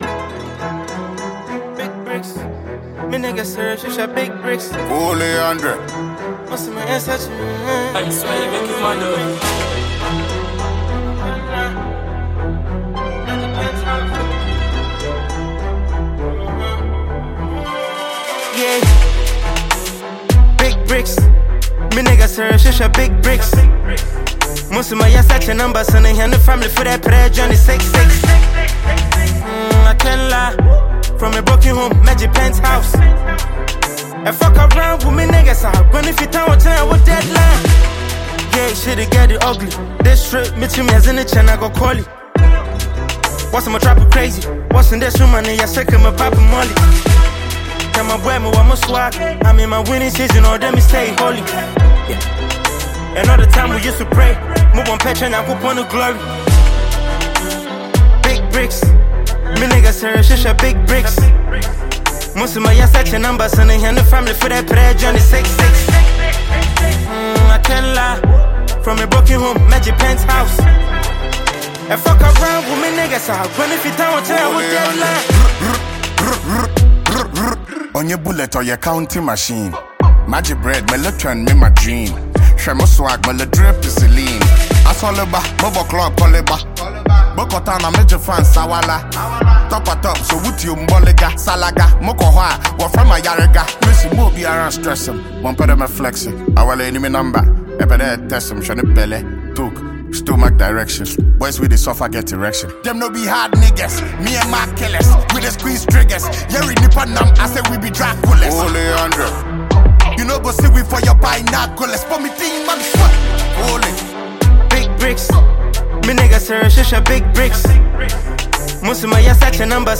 Genre: The song leans into hip-hop / drill-inspired rap
What makes the song stand out is its street-focused feel.